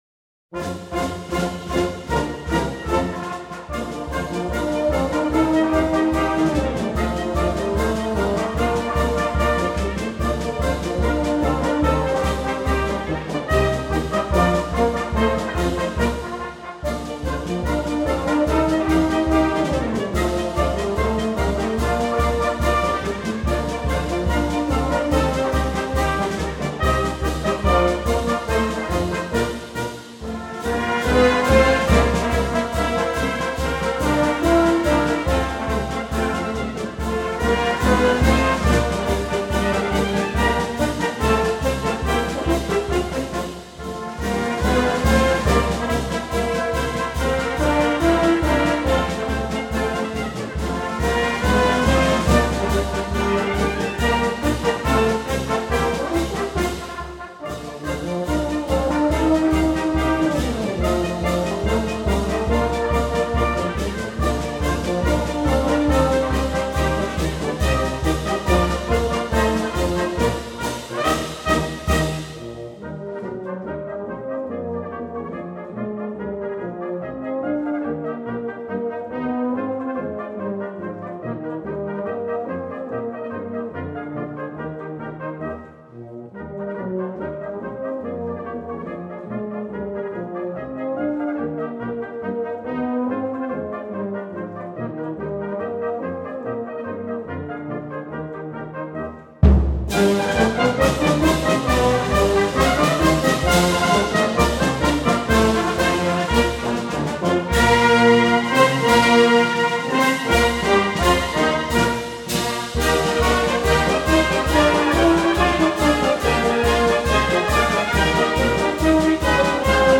La Fanfare d’Albeuve-Enney: La Fanfare du Printemps (Marche)